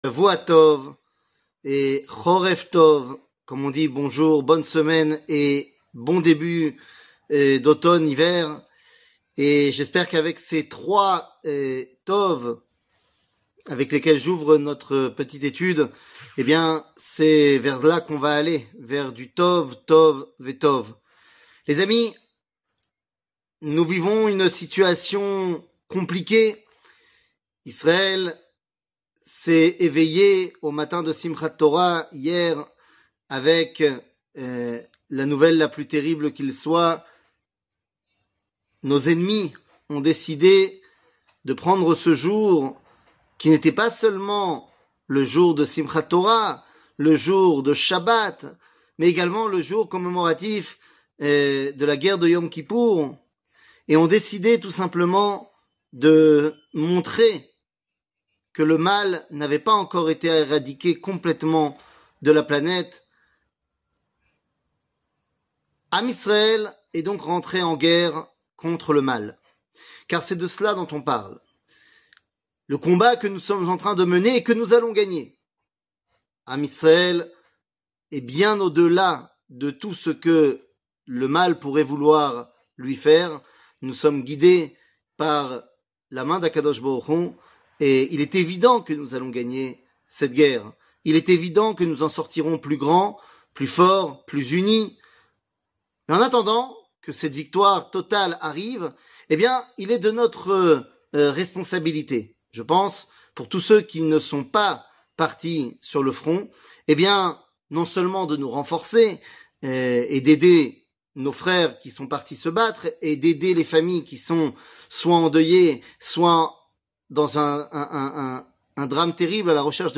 L'Eternite d' Israel ne mentira pas ! 1 00:12:02 L'Eternite d' Israel ne mentira pas ! 1 שיעור מ 08 אוקטובר 2023 12MIN הורדה בקובץ אודיו MP3 (11.01 Mo) הורדה בקובץ וידאו MP4 (19.68 Mo) TAGS : שיעורים קצרים